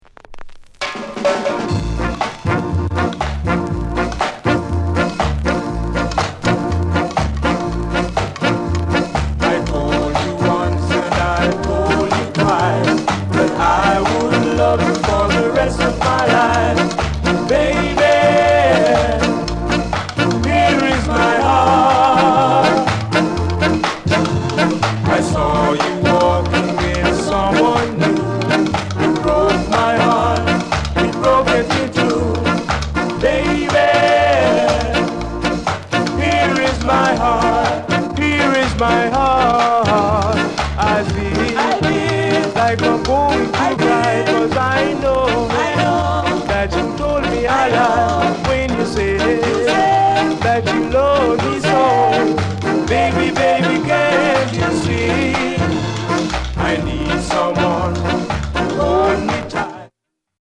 SOUND CONDITION A SIDE VG(OK)
NICE SKA INST